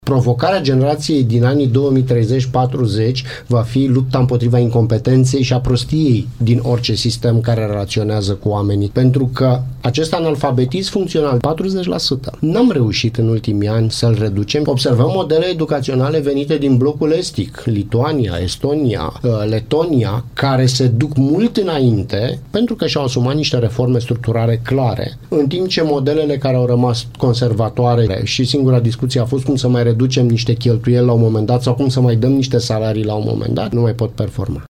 într-o dezbatere la Radio Timișoara.